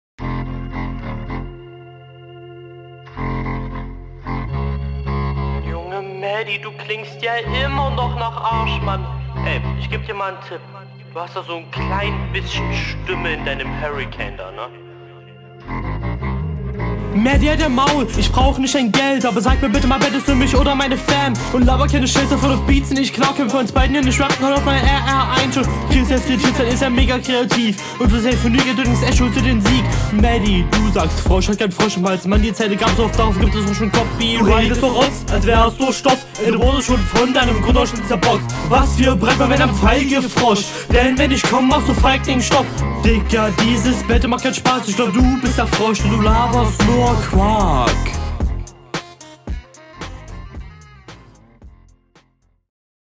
Rückrunde 2
Flow: Dadurch, dass dir der Beat davonläuft bzw. deine Zeilen zu lang sind schmeißt es …